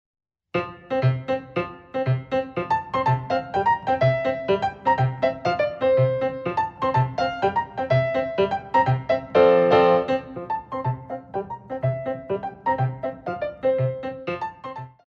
Ballet Class Music For First Years of Ballet